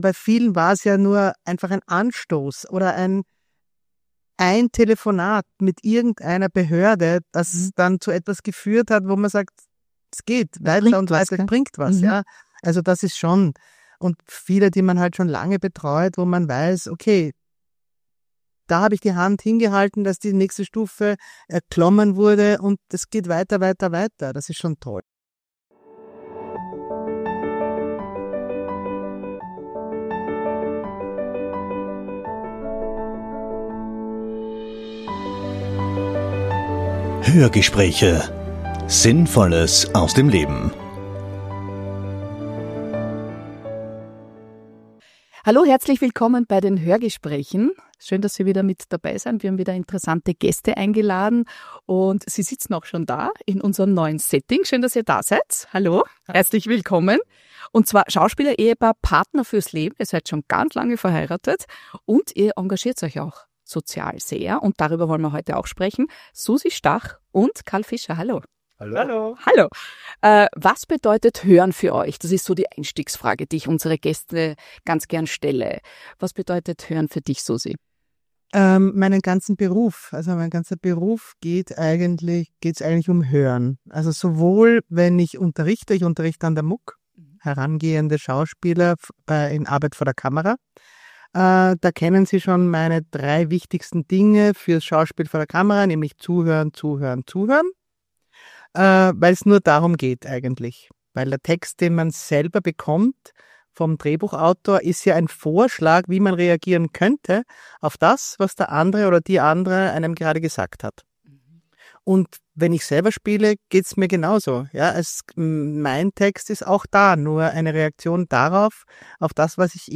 In einem Punkt sind sich beide einig: Gutes Hören ist für ihr Leben unverzichtbar. Sehr sympathische Gespräch mit dem österreichischem Schauspielerehepaar!